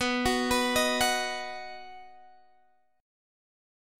B Chord
Listen to B strummed